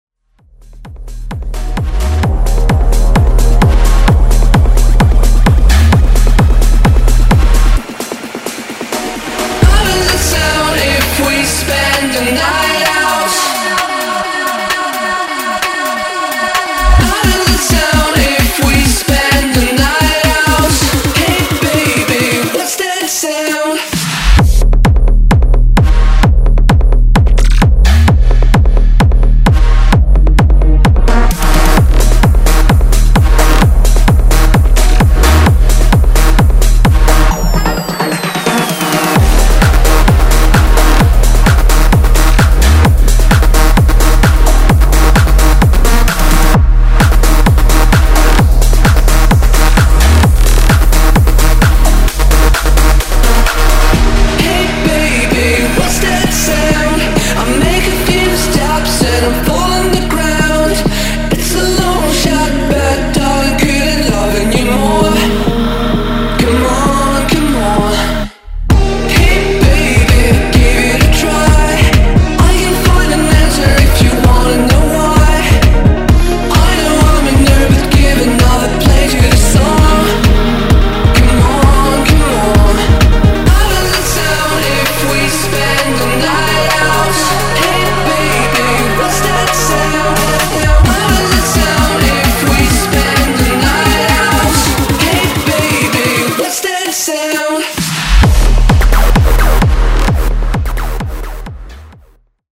Genres: MASHUPS , TOP40
Clean BPM: 116 Time